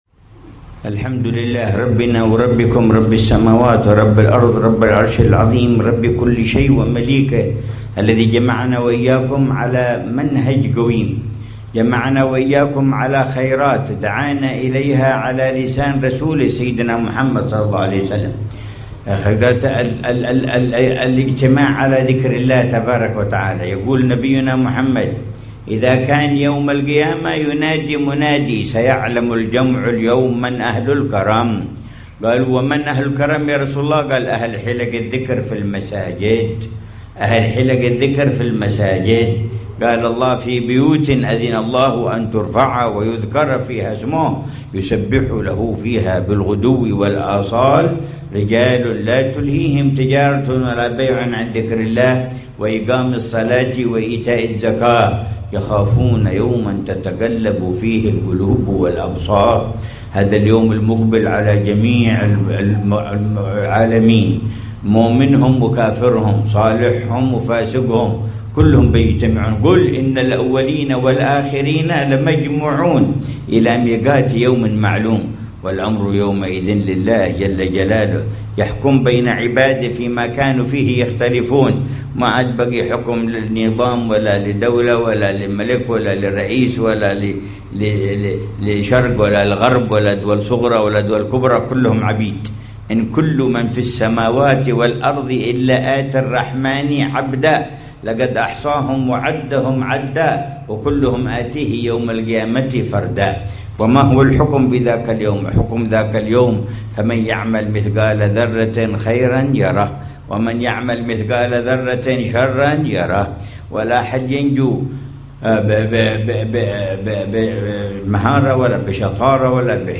مذاكرة العلامة الحبيب عمر بن محمد بن حفيظ في مجلس الذكر والتذكير في الزيارة السنوية في مسجد الإمام محمد بن علي مولى الدويلة، في منطقة يبحر، غربي شعب النبي هود عليه السلام، بوادي حضرموت، 17 محرم 144